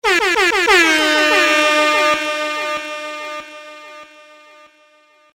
Sound Effects - Air Horns 01
Tags: hip hop